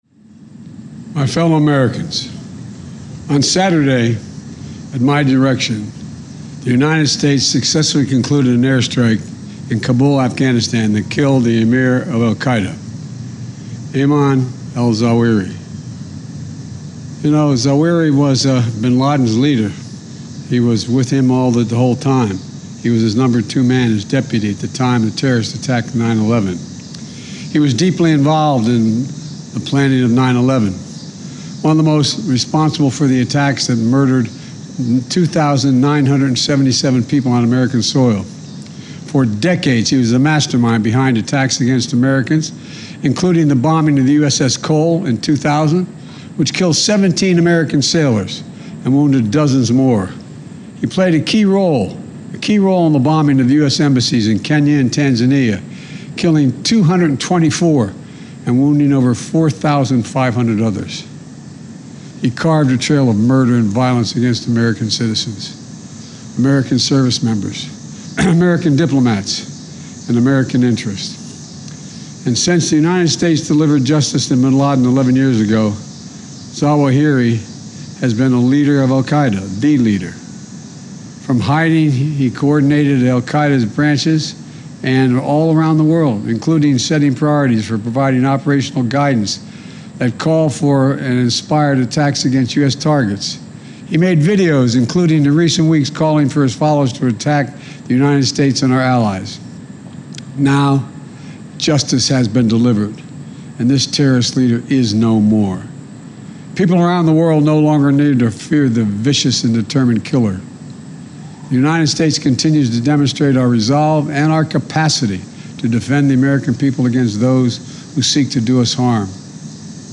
Joe Biden
delivered 1 August 2022, White House, Washington, D.C.